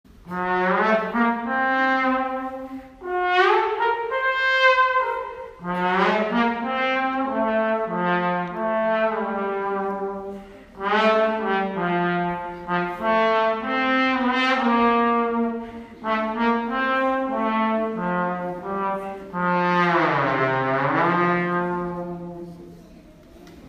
東京藝術大学の学生６名が来校し、金管ア ンサンブルをきかせてもらいました。
楽器紹介では、「チューバ」「ユーフォニ アム」「ホルン」「トロンボーン」「トラ ンペット」の名前と音色、特徴を聞かせて もらいました。